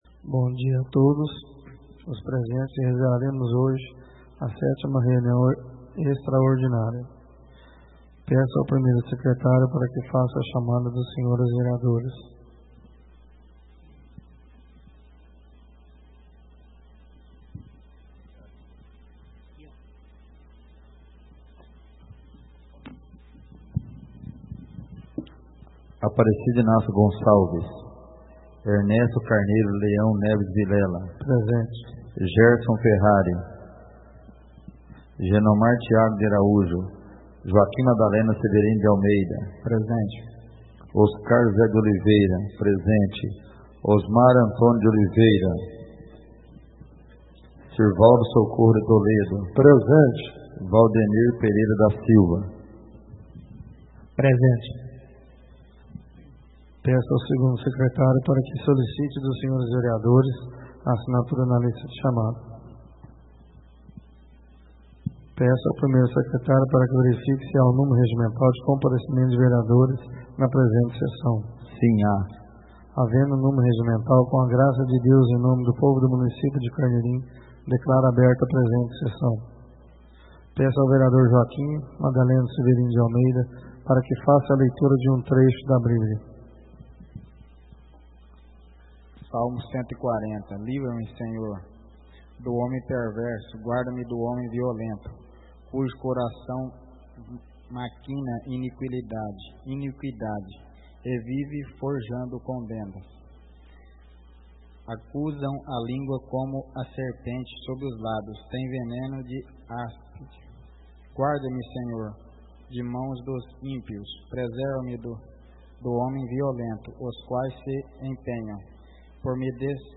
Sétima sessão ordinária de 2013, realizada no dia 23 de Novembro de 2013, na sala de sessões da Câmara Municipal de Carneirinho, Estado de Minas Gerais.